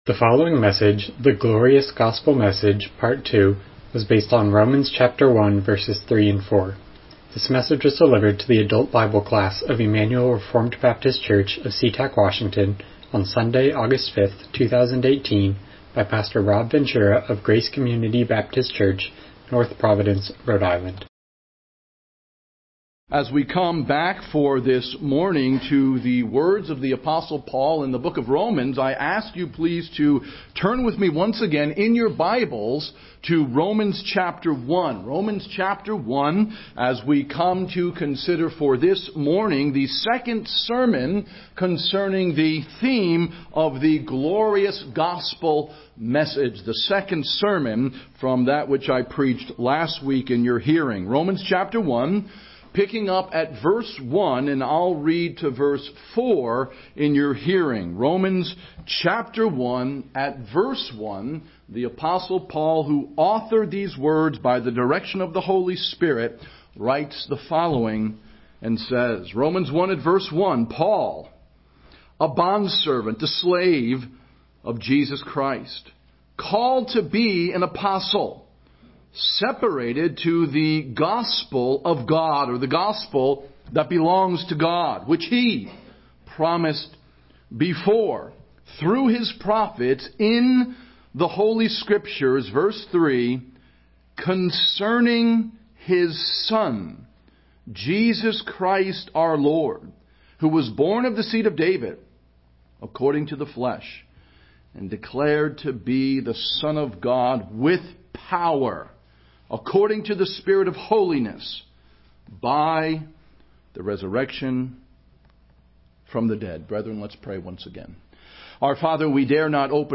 Romans 1:3-4 Service Type: Sunday School « The Glorious Gospel Message